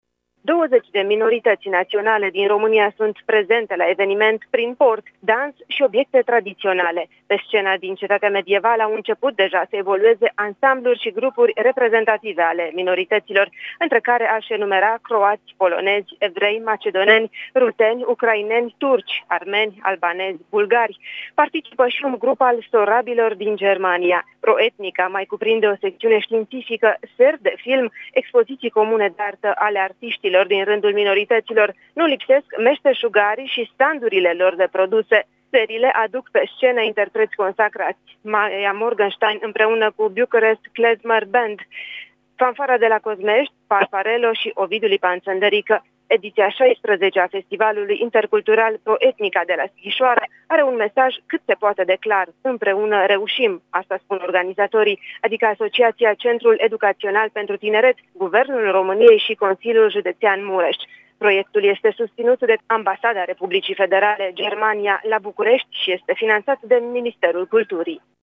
Relatează